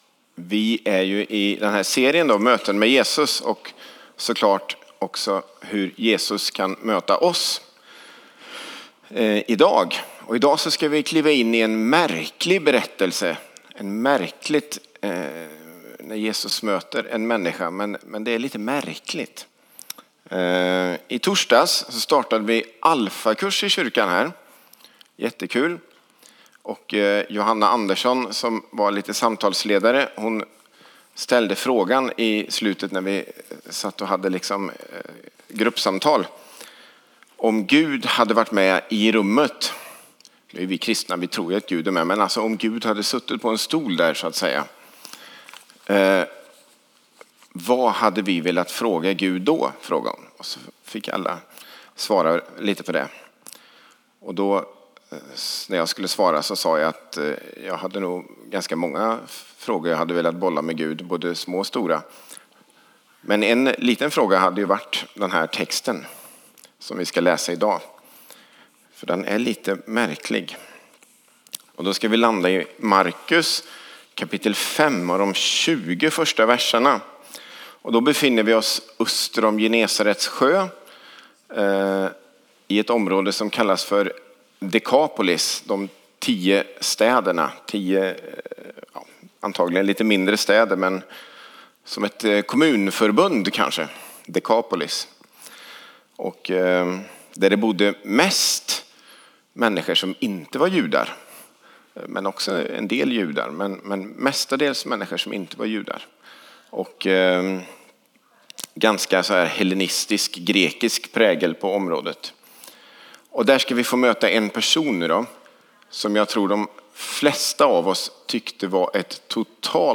Predikan
A predikan from the tema "Möten med Jesus."